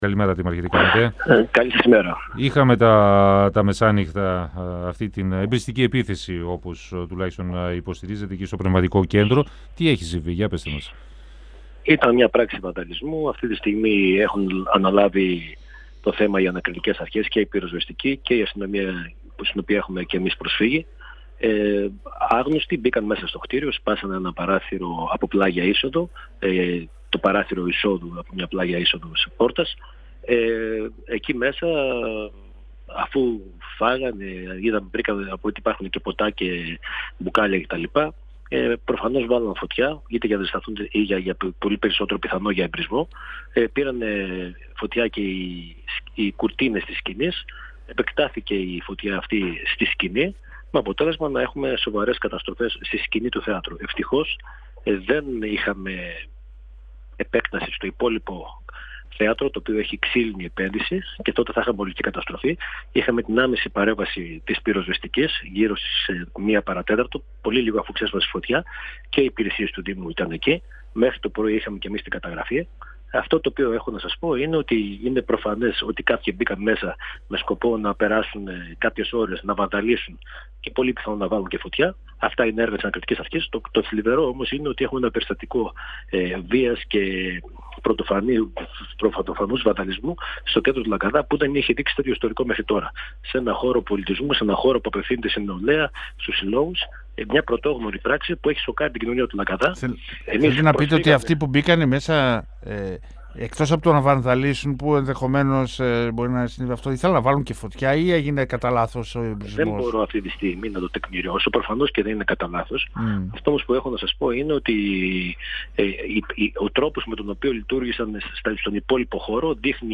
O δήμαρχος Λαγκαδά, Γιάννης Καραγιάννης,  στον 102FM του Ρ.Σ.Μ. της ΕΡΤ3